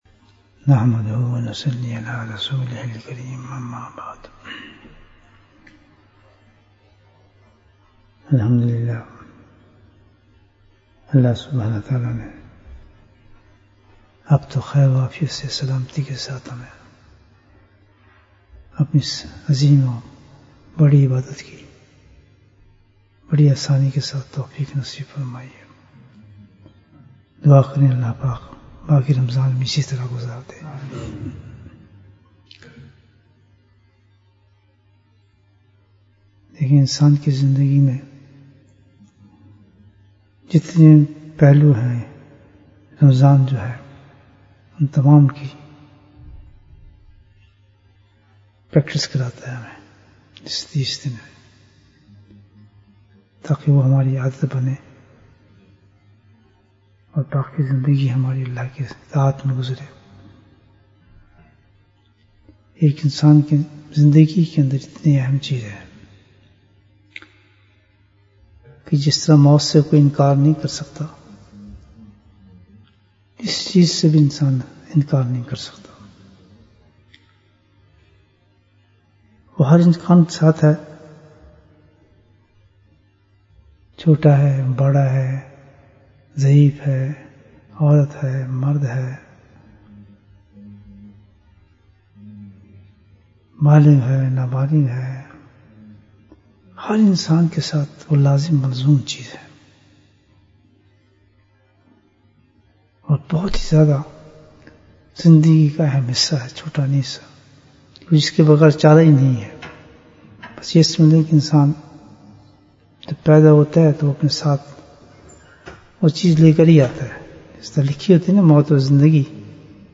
پریشانیوں کا حل Bayan, 45 minutes20th April, 2021